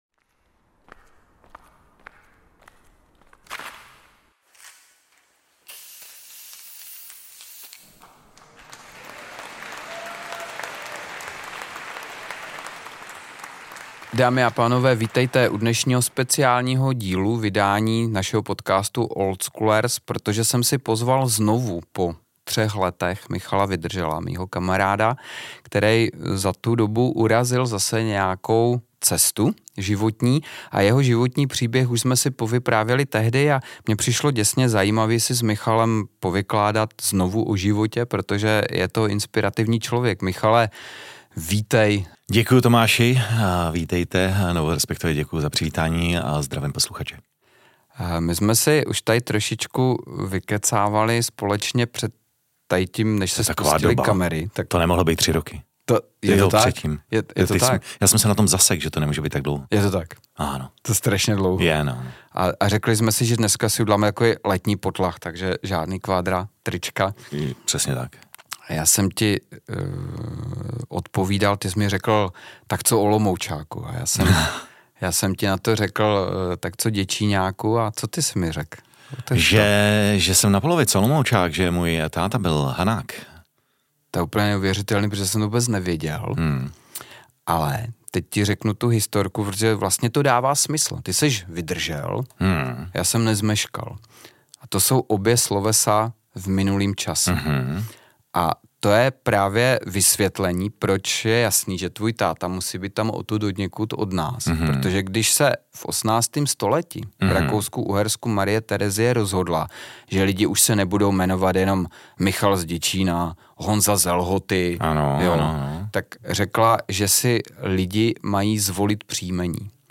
Neuvěřitelně formativní rozhovor o lidském bytí a nebytí.